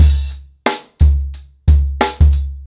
TheStakeHouse-90BPM.17.wav